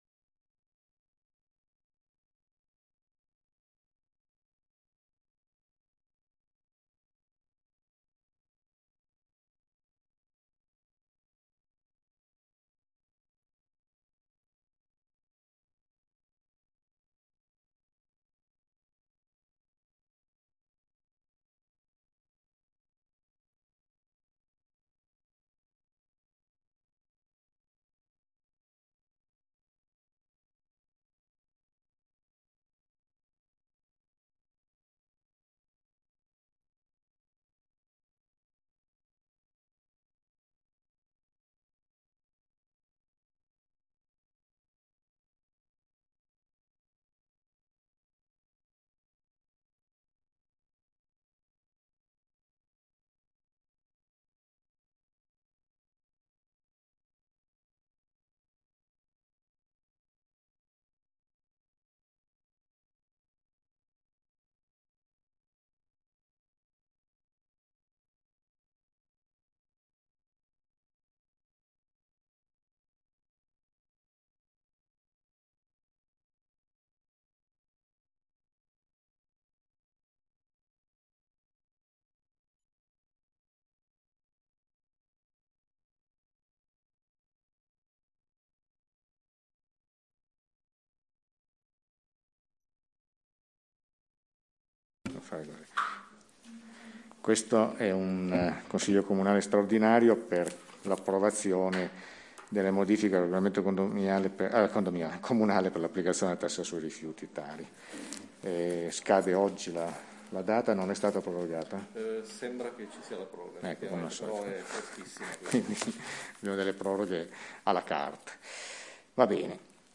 Registrazione Consiglio comunale - Commune de Pecetto Torinese